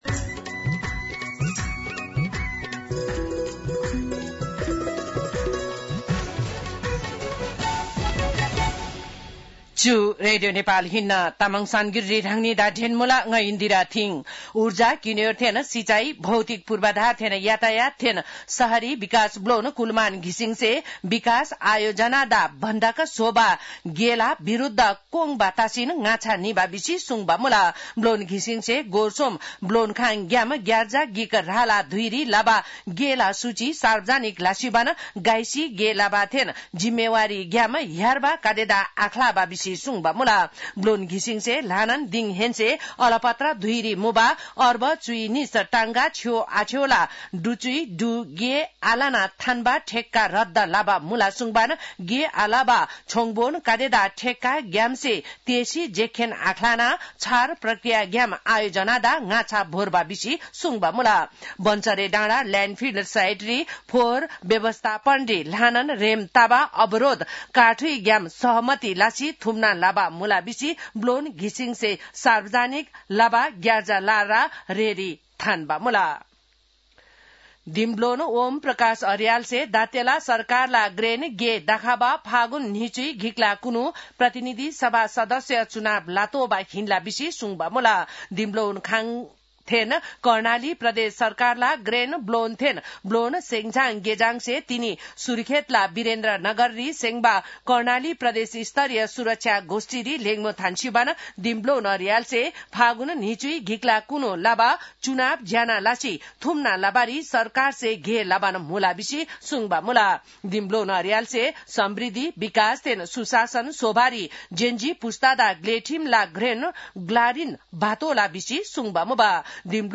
तामाङ भाषाको समाचार : ९ पुष , २०८२